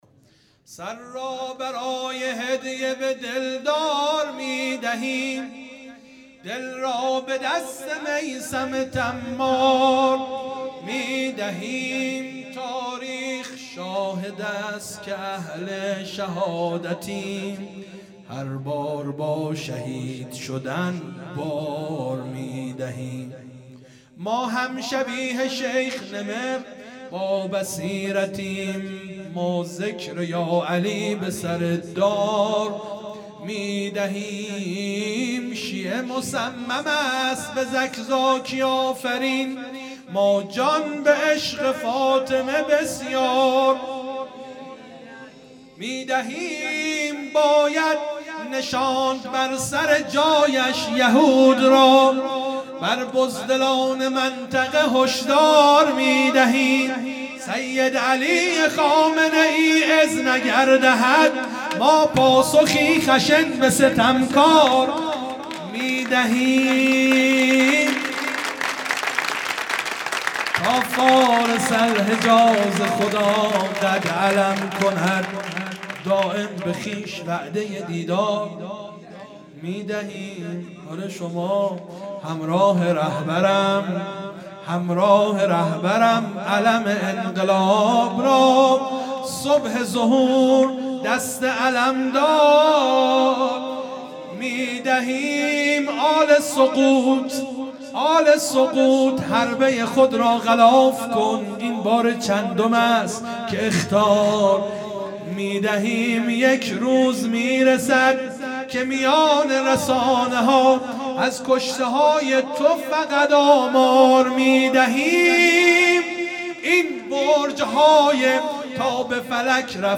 مدح
آغاز امامت امام زمان (عج) | ۷ آذر ۹۶